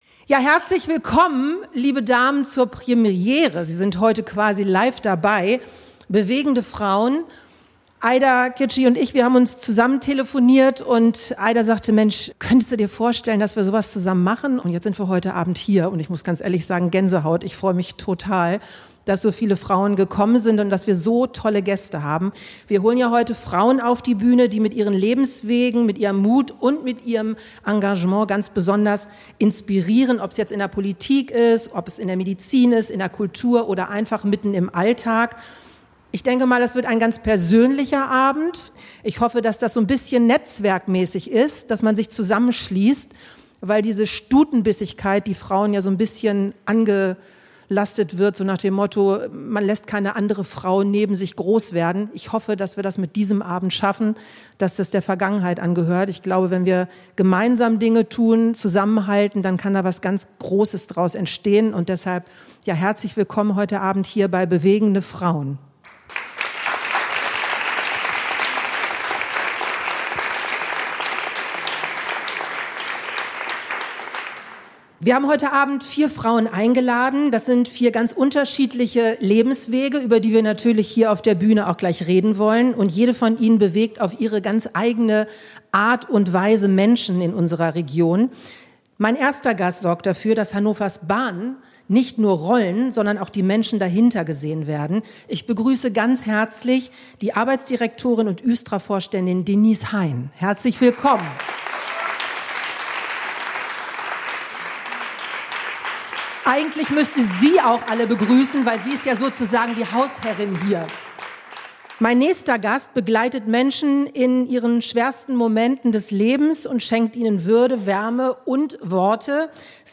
"Bewegende Frauen" - eine neue Talkreihe ~ NDR 1 Niedersachsen - Kulturspiegel Podcast
Es wird persönlich, bewegend und unterhaltsam.